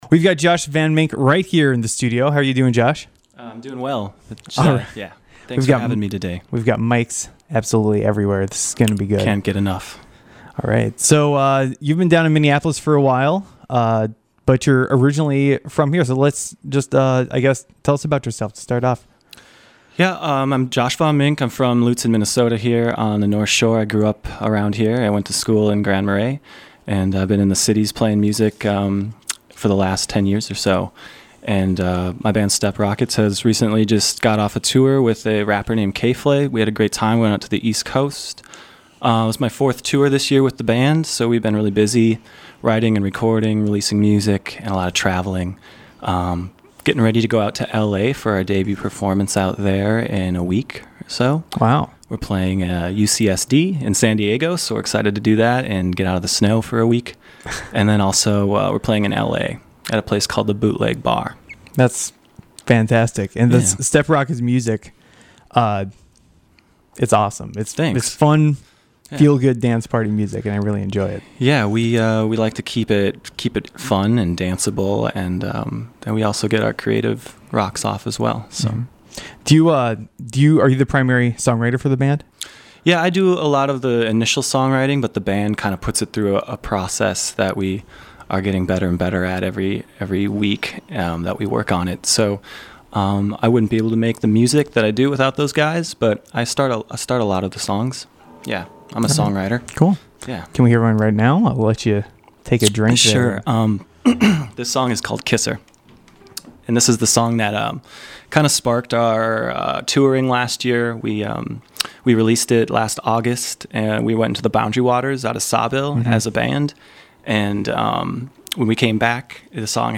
special live set